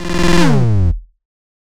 Index of /phonetones/unzipped/Google/Android-Open-Source-Project/notifications/ogg